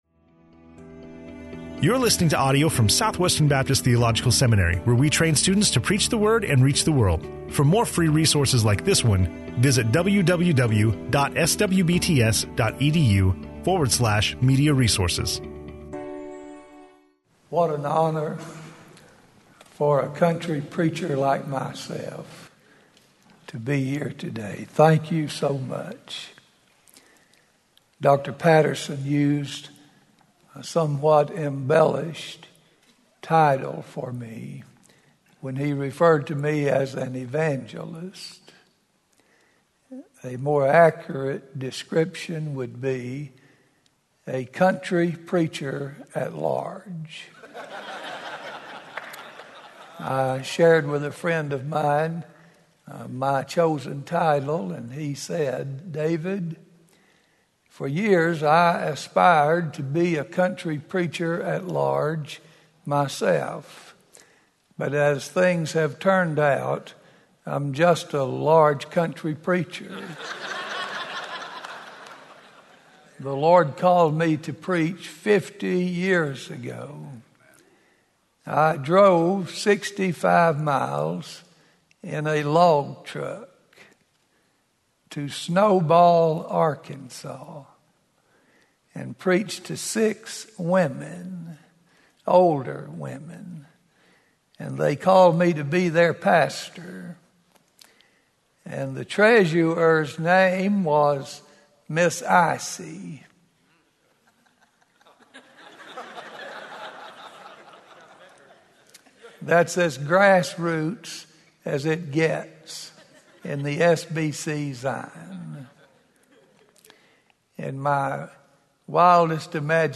speaking on Isaiah 6:1-8 in SWBTS Chapel